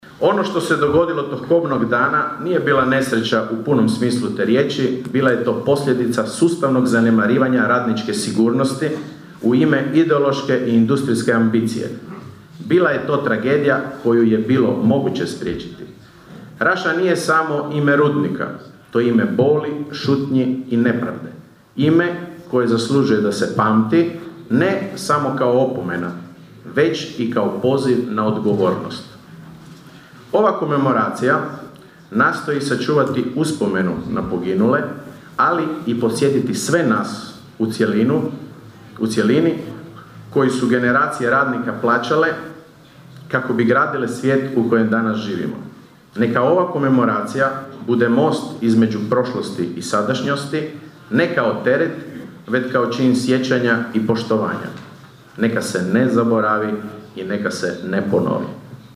U Raši je održana komemoracija povodom 86. obljetnice rudarske tragedije iz 1940. godine u kojoj je poginulo 185 rudara. Održani su govori, misa i prigodni program.